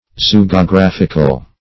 Zoogeographical \Zo`o*ge`o*graph"ic*al\